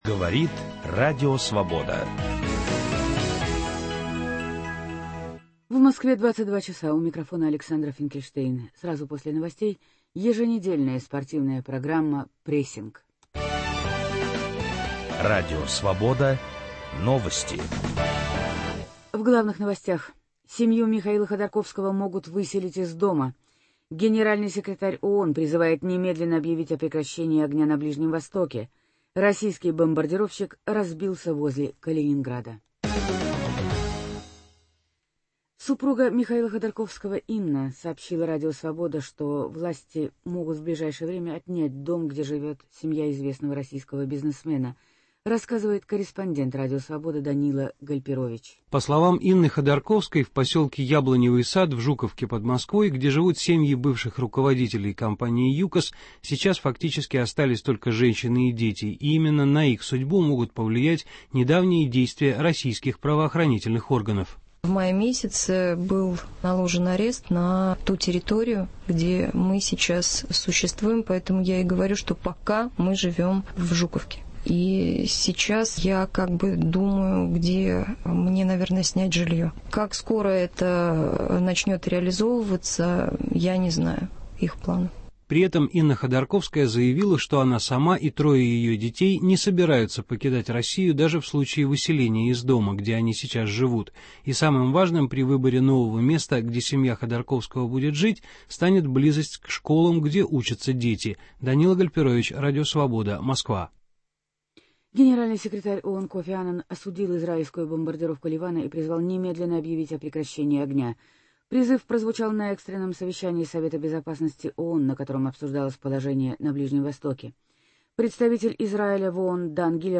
В спортивной программе "Прессинг" - не только свежая информация, анализ и размышления, но и голоса спортсменов и тренеров всего мира с откровениями о жизни, о партнерах и соперниках. Речь не только о самом спорте, ибо он неотделим от социальных, экономических, нравственных и национальных проблем.